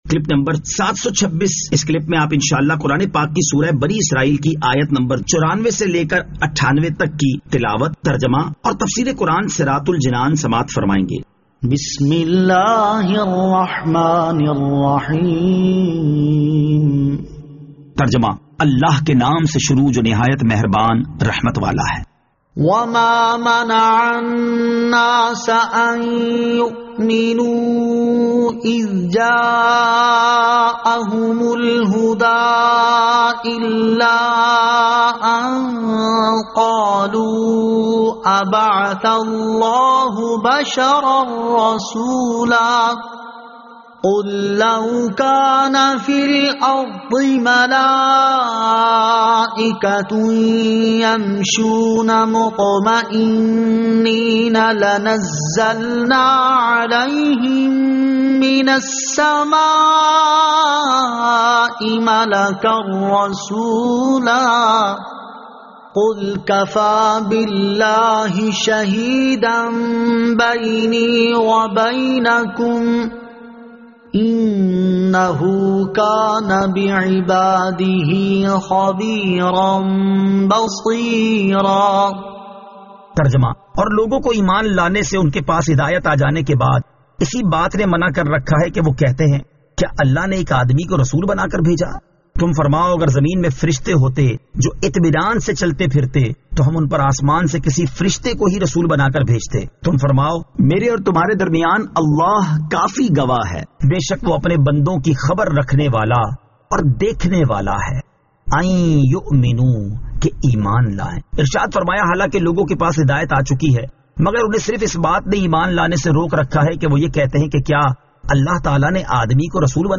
Surah Al-Isra Ayat 94 To 98 Tilawat , Tarjama , Tafseer